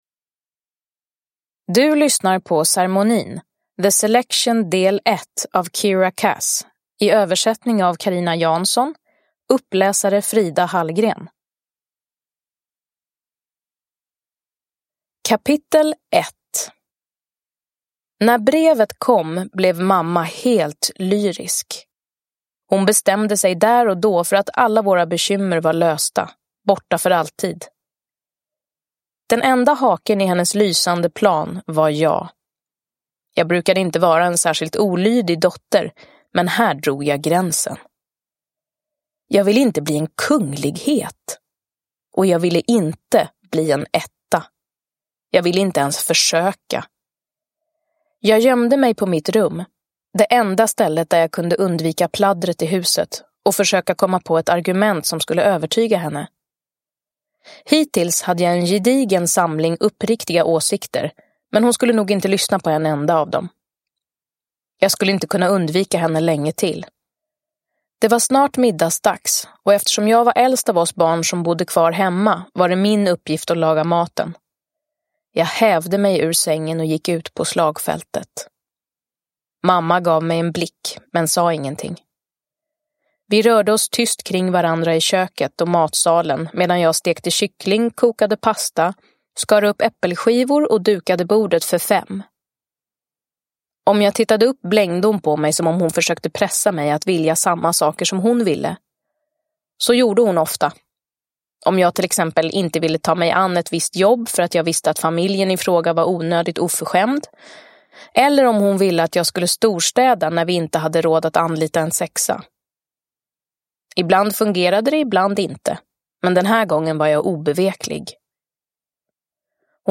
Ceremonin – Ljudbok – Laddas ner
Uppläsare: Frida Hallgren